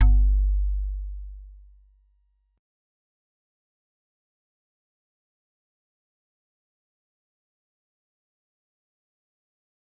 G_Musicbox-G1-pp.wav